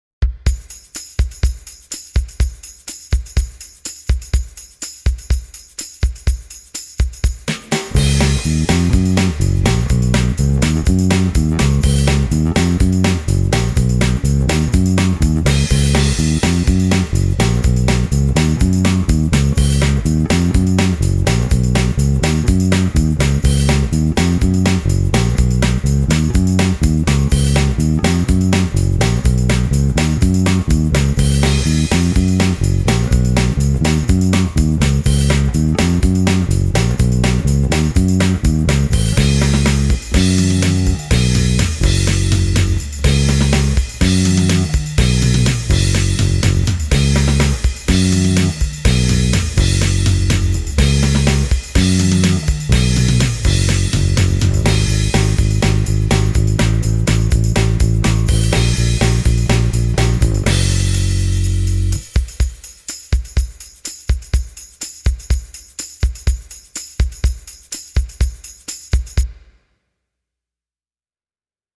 I used my own 1976 Fender as a benchmark, and the Green P-Bass growls just like it should. The low register is punchy, and the top end is open, but never sharp or brittle.
I have chosen to record a little Motown-tribute as a demo song. The Green P-Bass went through a SansAmp Bass Driver and then into a Focusrite interface: